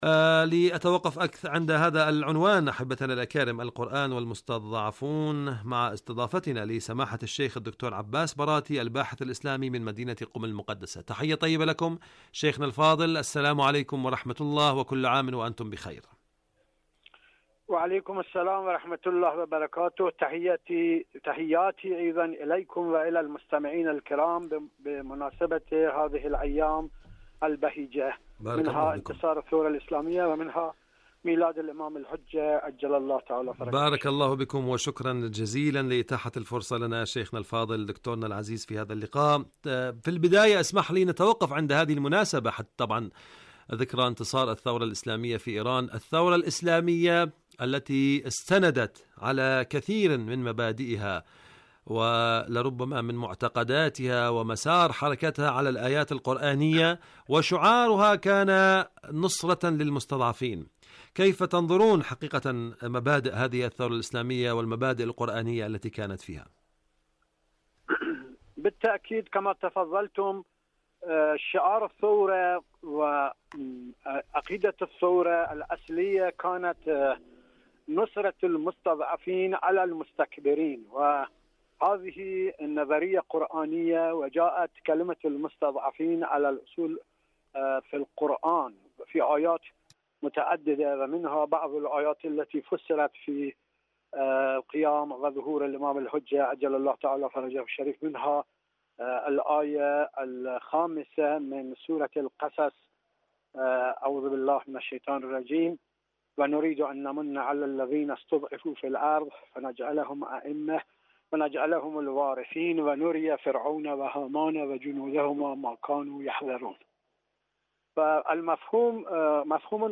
القرآن والمستضعفون.. مقابلة